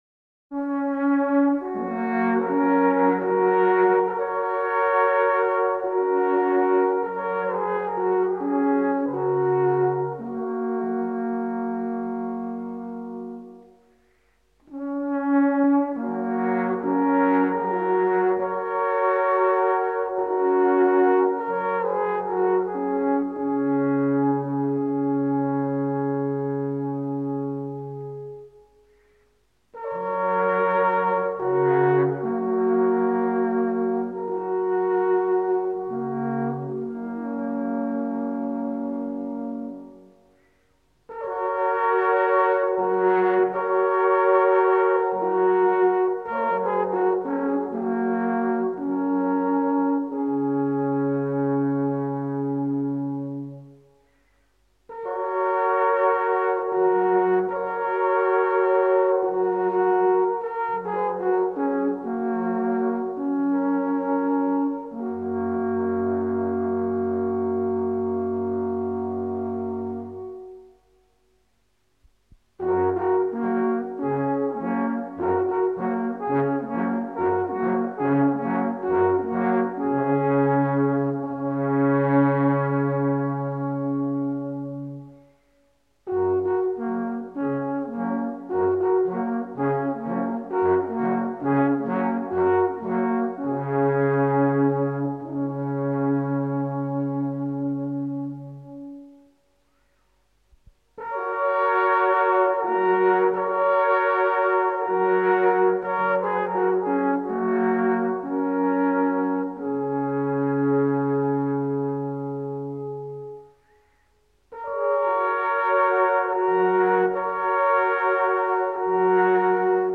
Les Armaillis du Grand Muveran: Ranz des vaches des Ormonts (Swiss Pastoral Melody)